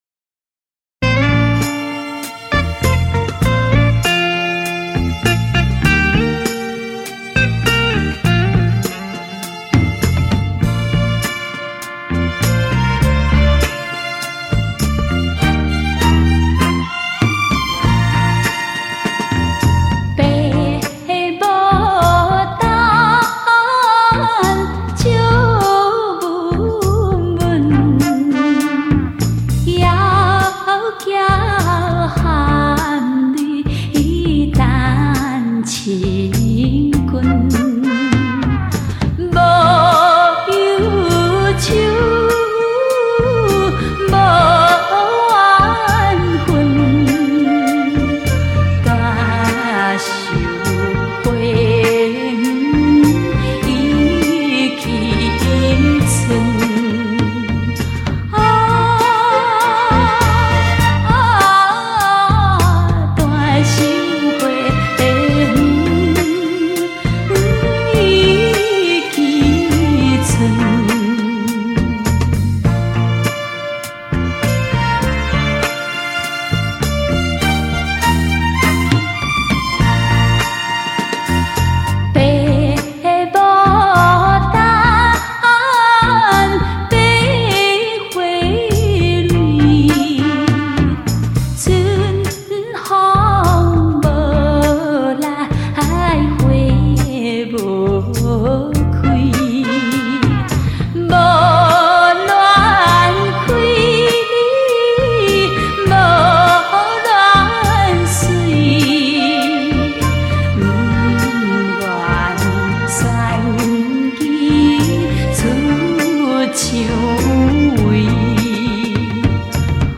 人声厚润 配器通透 经典名曲 真挚动人
日本重新制版